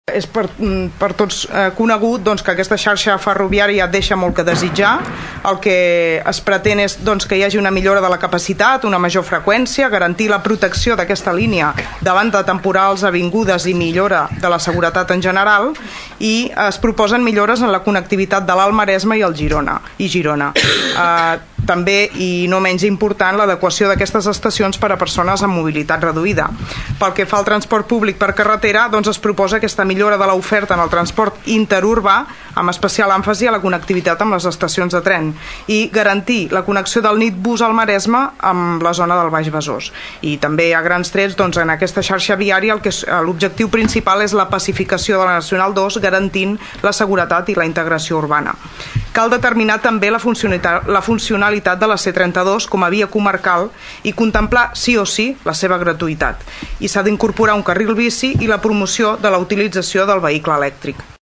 La regidora de transports de l’Ajuntament de Tordera, Sílvia Català, va recordar durant el plenari que les administracions de la comarca estan lluitant, des de fa anys, per aconseguir una mobilitat adequada, sostenible i segura.